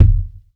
impact_deep_thud_bounce_01.wav